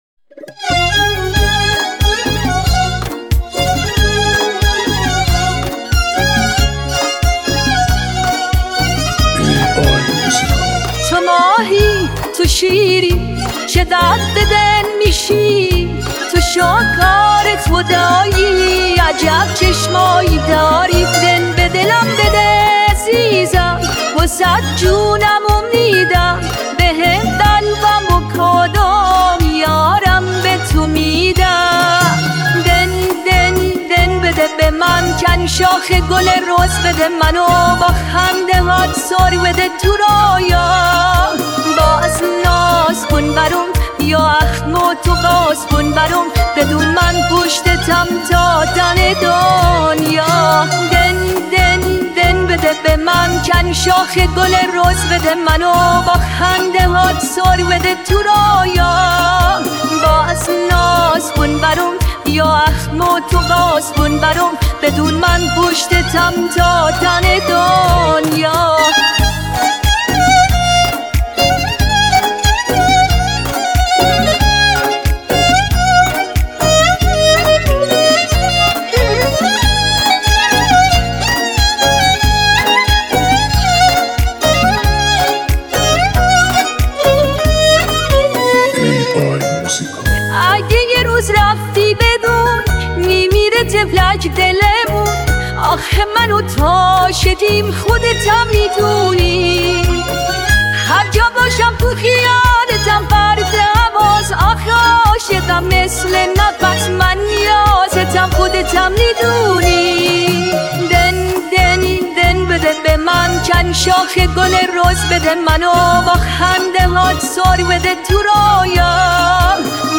اهنگ سبک کانتری